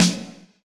drumOff.wav